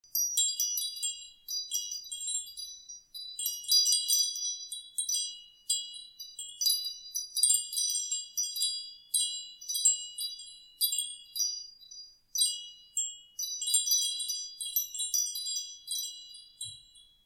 Klangspiel „Flores“, 3 Bambusstäbe & 9 Klangröhren
mit 3 horizontalen Bambusstäben und 9 Klangröhren aus Aluminium
Unter dem Reiter "Medien" finden Sie ein zu dem Windspiel passendes Klangbeispiel
Dieses wunderschöne Klangspiel vereint Bambus und Aluminium zu einer harmonischen Poesie aus liebreizender Optik und meditativer Akustik.
Windspiel
Klangbeispiel